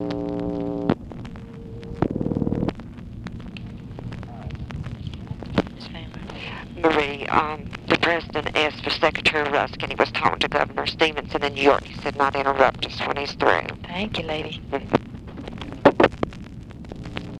Conversation with TELEPHONE OPERATOR
Secret White House Tapes